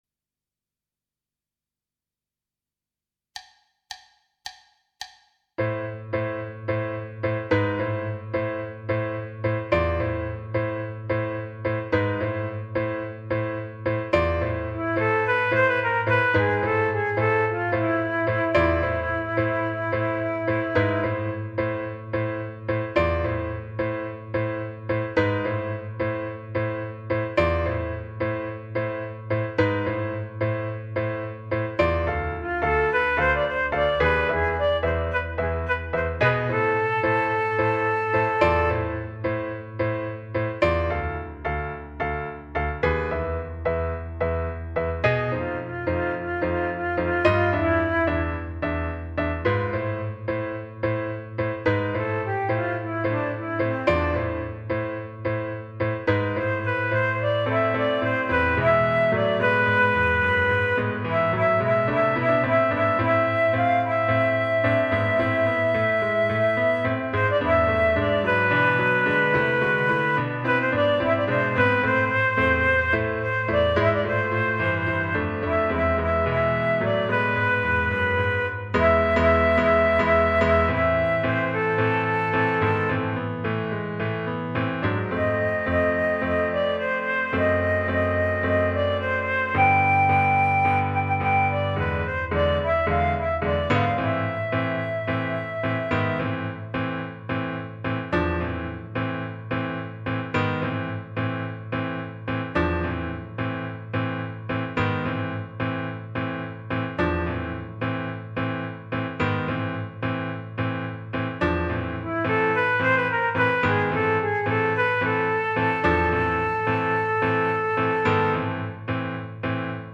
minus Instrument 2